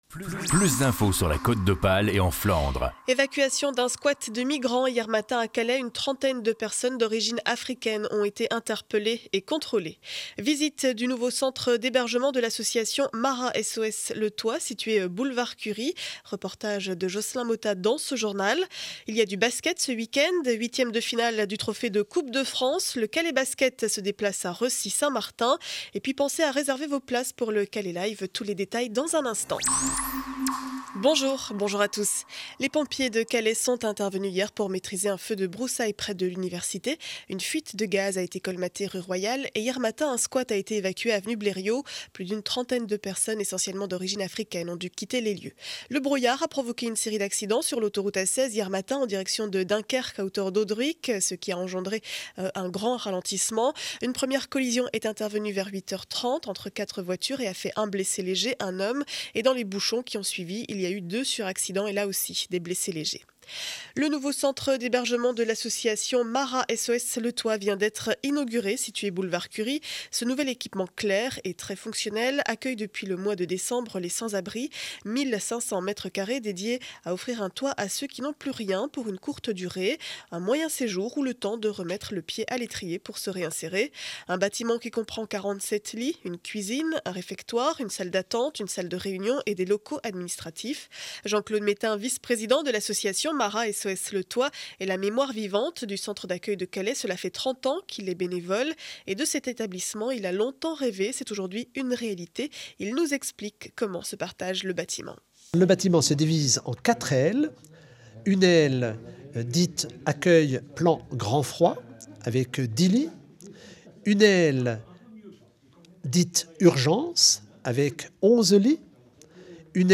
Journal du vendredi 16 mars 2012 7 heures 30 édition du Calaisis.